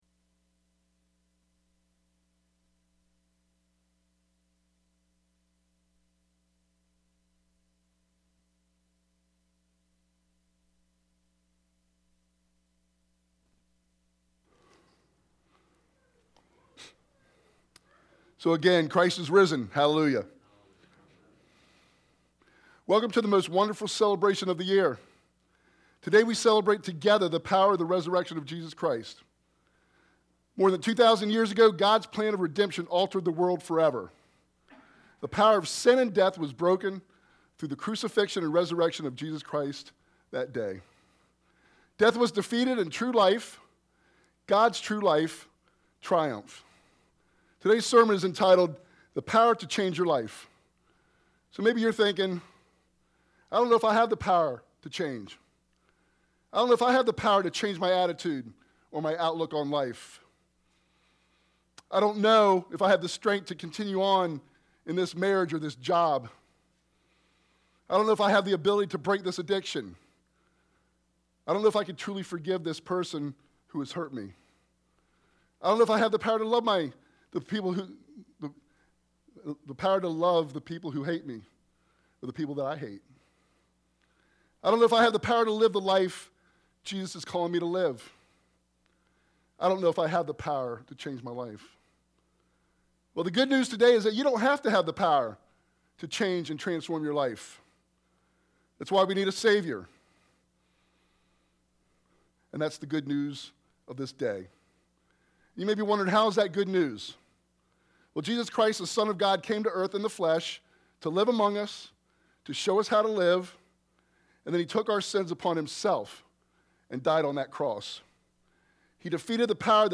Idaville Church » Sermons